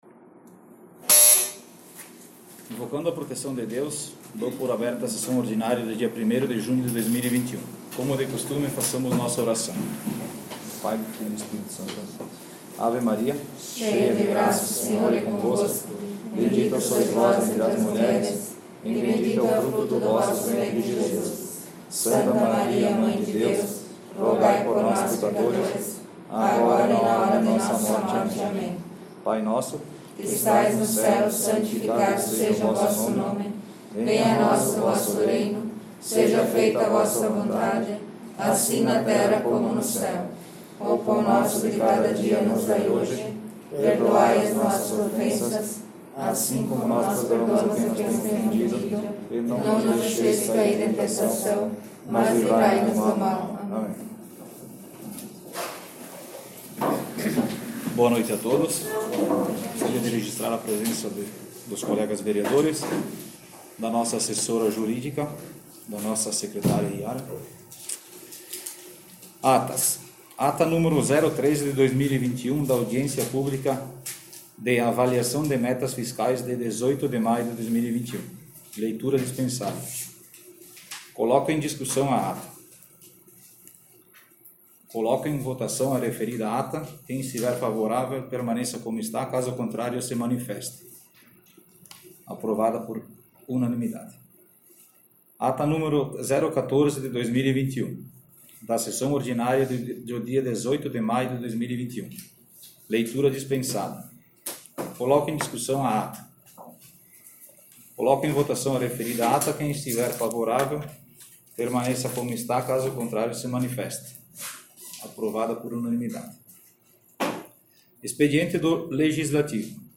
Sessão Ordinária 01/06/2021